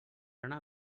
speech-commands